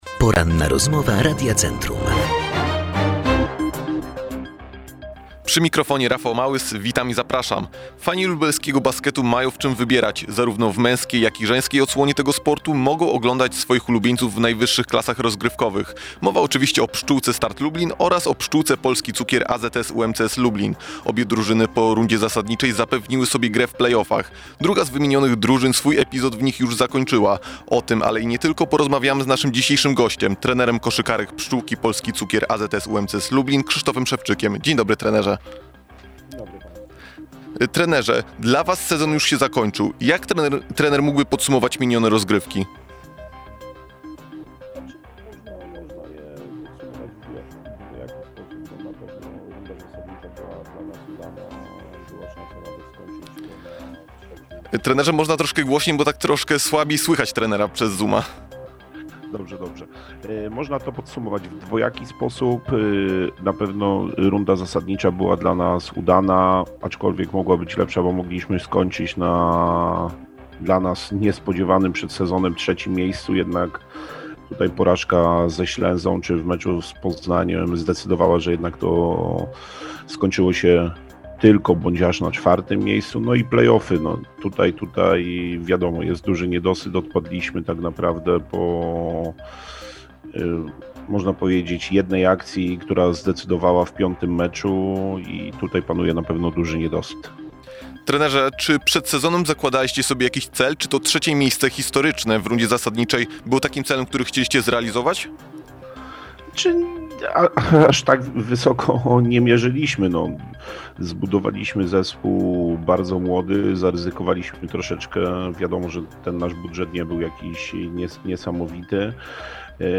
Cala-rozmowa.mp3